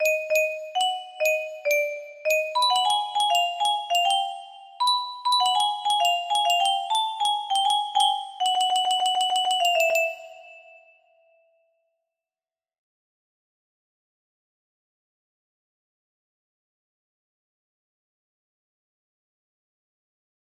The funny music box melody
Grand Illusions 30 (F scale)